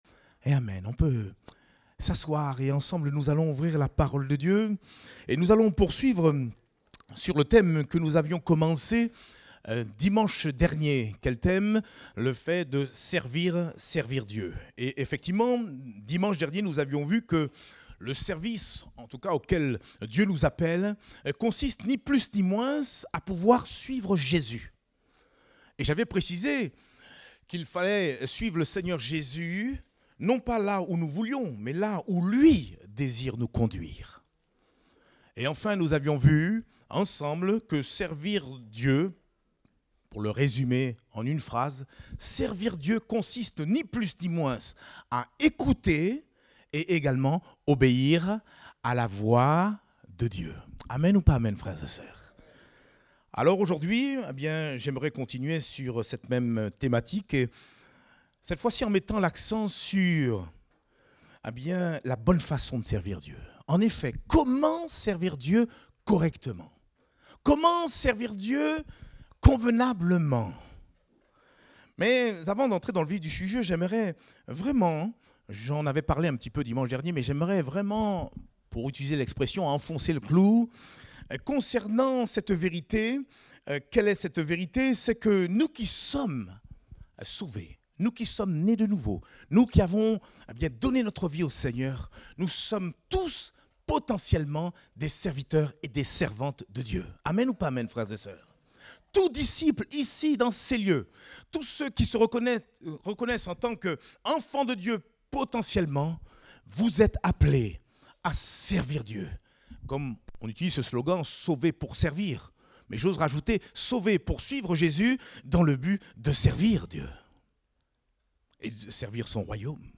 Date : 10 septembre 2023 (Culte Dominical)